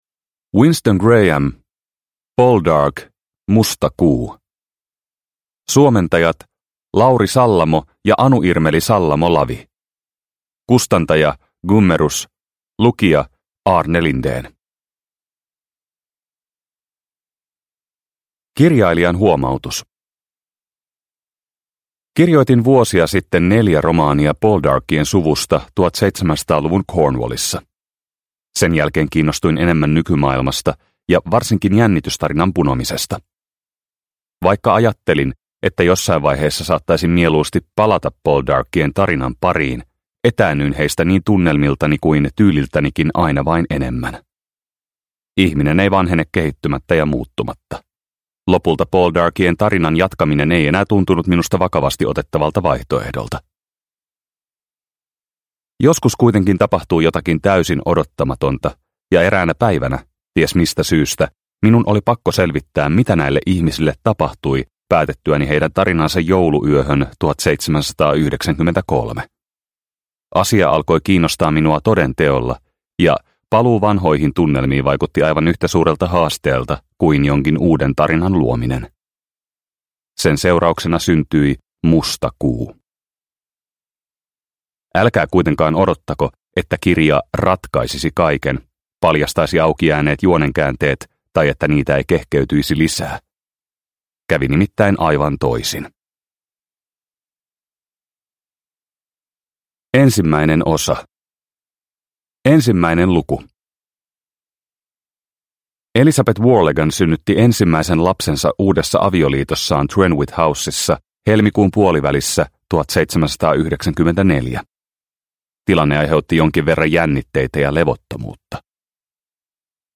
Poldark - Musta kuu – Ljudbok – Laddas ner